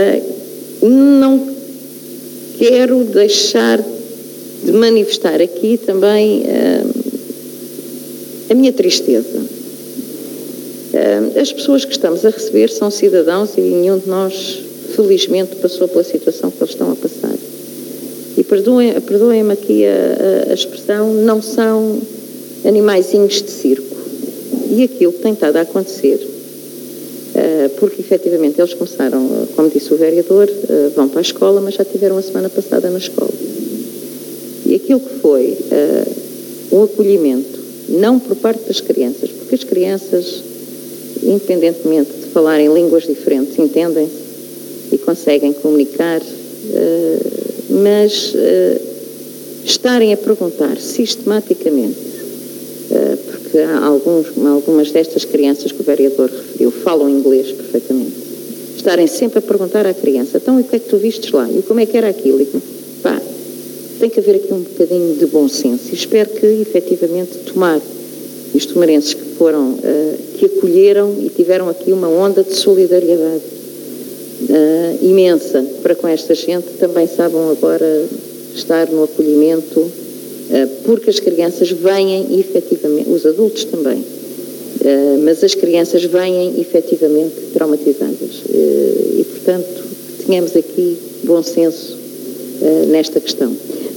“As pessoas que estamos a receber da Ucrânia são cidadãos, não são animaizinhos de circo”, disse a presidente da câmara de Tomar na reunião de hoje, dia 21.